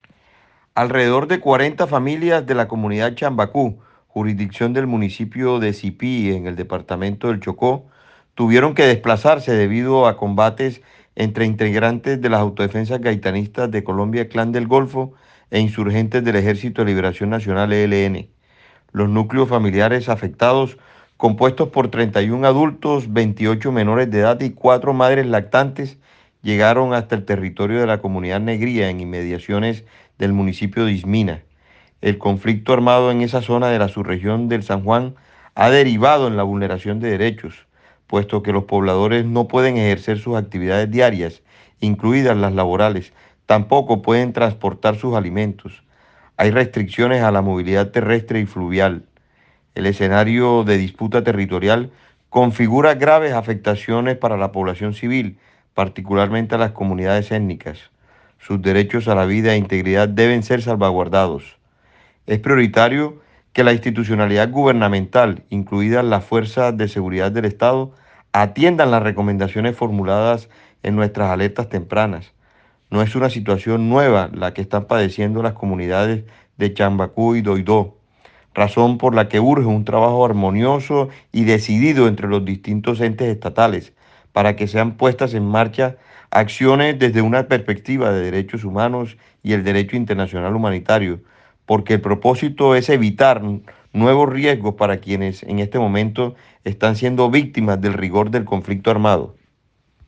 (Escuche el pronunciamiento del Defensor del Pueblo)